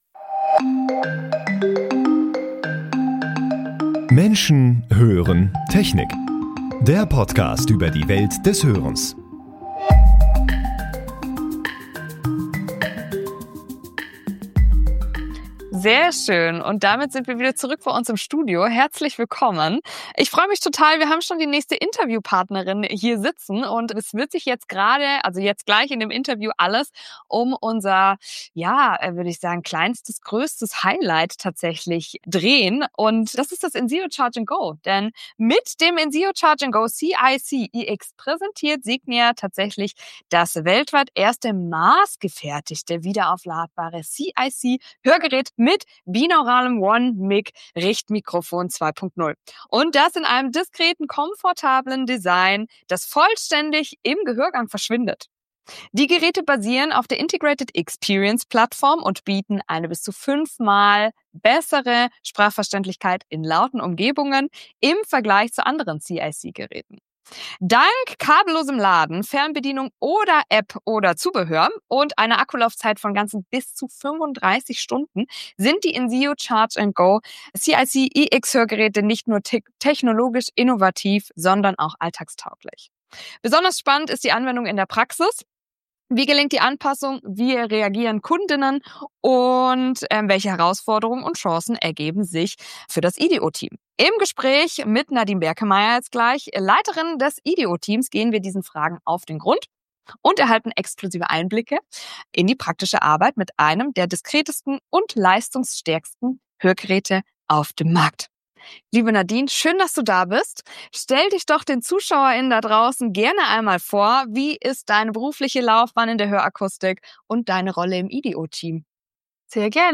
Dies ist Episode 4 der Interview-Reihe, die mit Entwicklerinnen und Entwicklern zum Thema Hörgeräte, digitale Services und Designs geführt wurde.